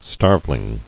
[starve·ling]